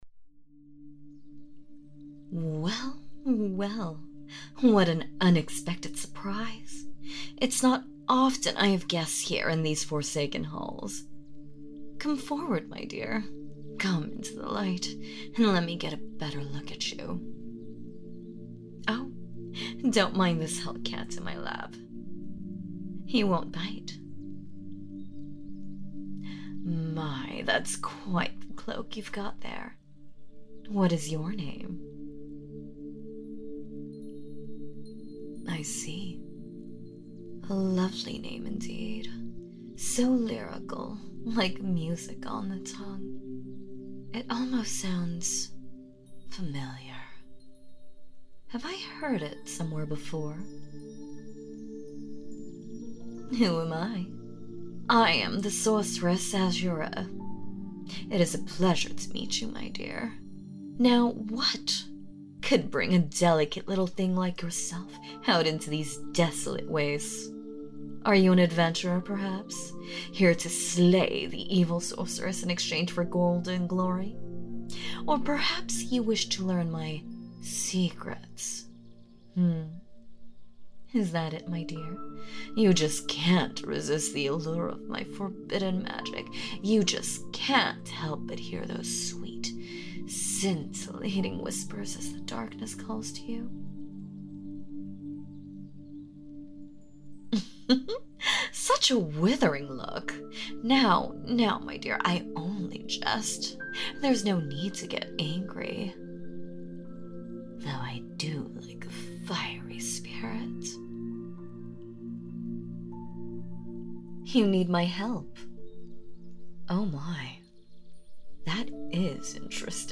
Note: So...one of my cats was sitting on my lap and wouldn't leave and wouldn't stop purring...so I decided to incorporate the purring sounds, haha...;;;;